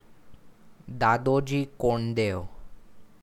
pronunciation (died 7 March 1647) was an administrator of the Pune jagir and the nearby Kondana fort.